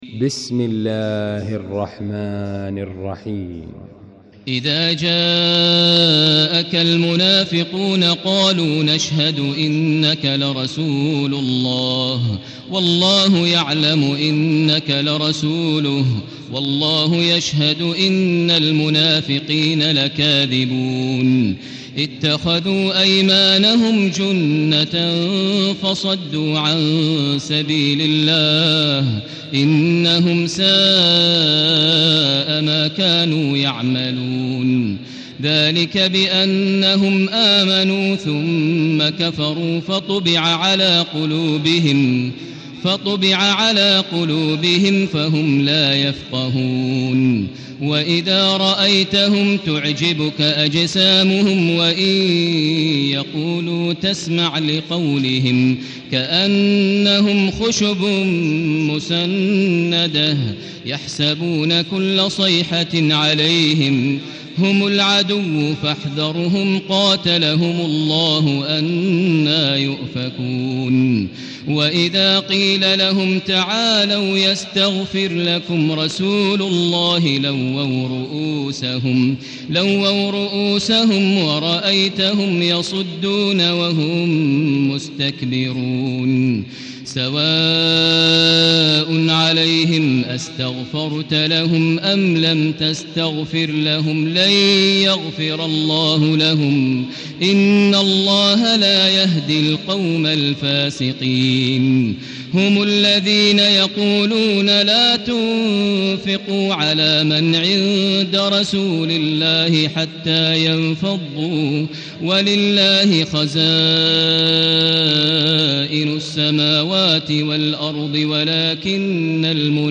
المكان: المسجد الحرام الشيخ: فضيلة الشيخ ماهر المعيقلي فضيلة الشيخ ماهر المعيقلي المنافقون